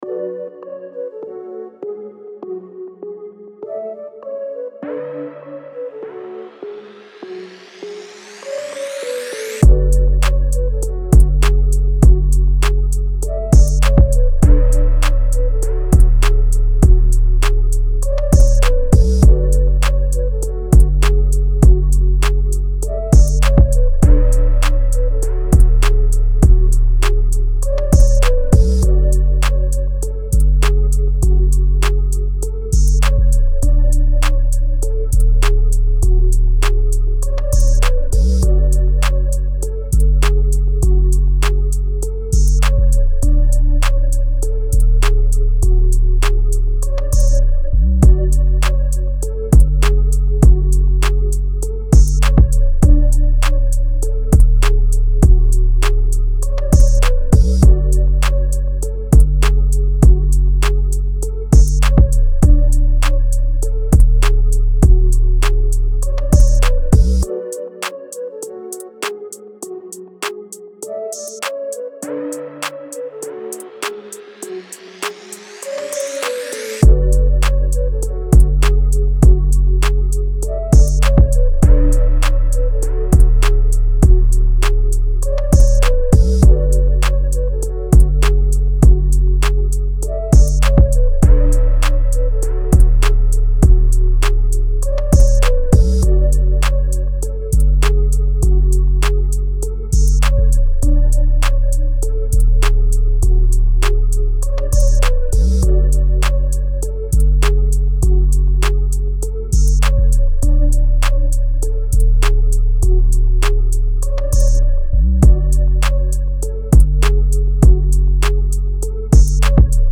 Hip Hop, R&B
F# Minor